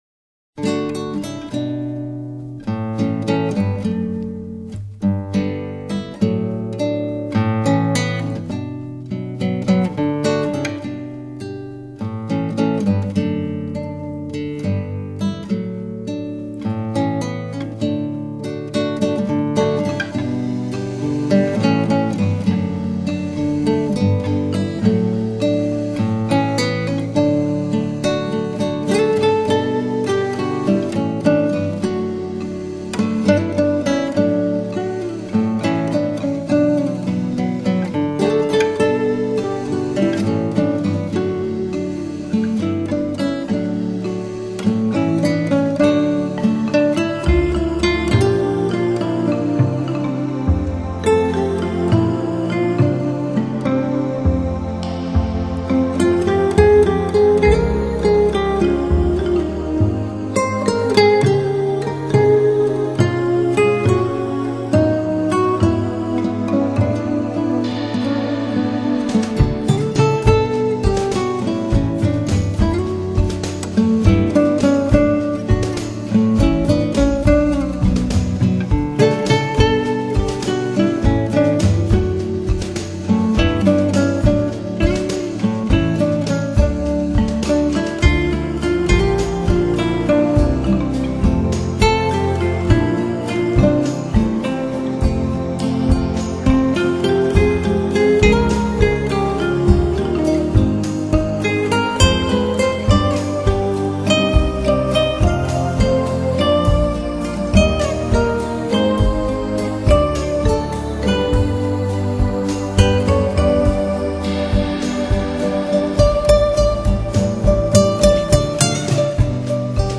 专辑类型：奔放辽阔的打击乐
以其擅长的吉他及打击乐呈现出蛮荒大地的辽阔。